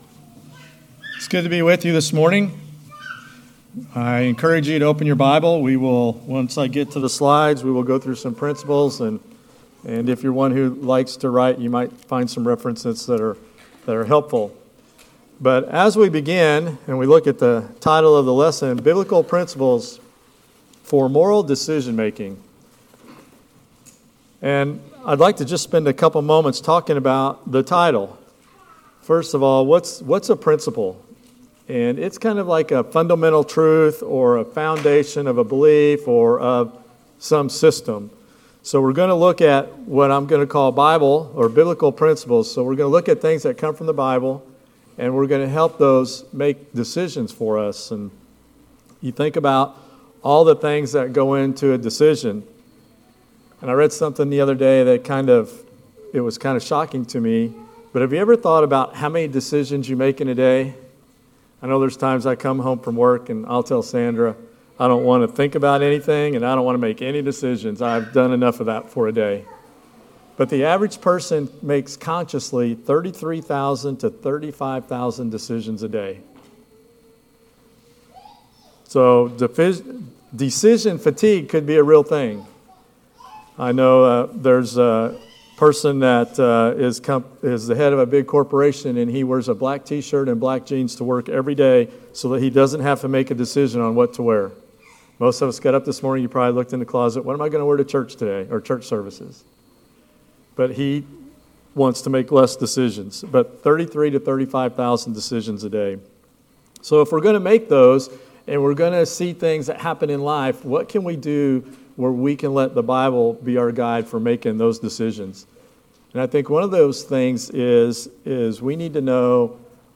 Sermon Archives Service Type: Sunday Evening Worship As we begin